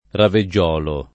raveggiolo [ rave JJ0 lo ] o raviggiolo [ ravi JJ0 lo ]